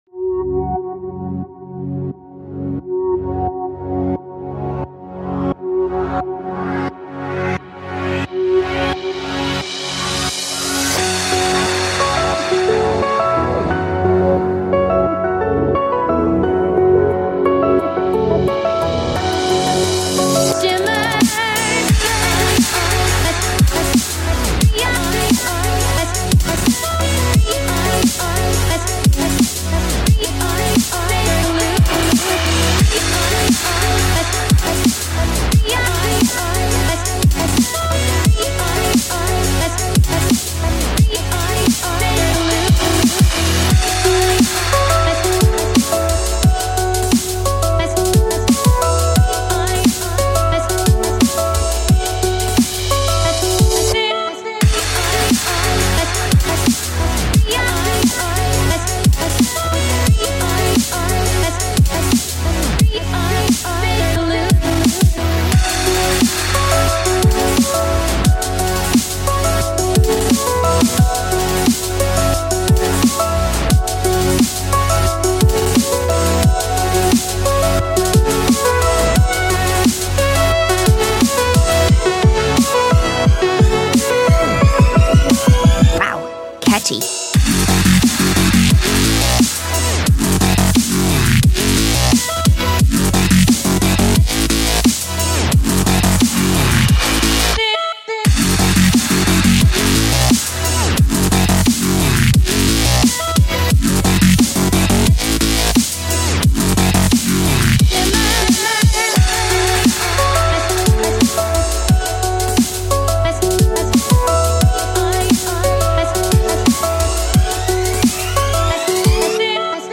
Drumstep!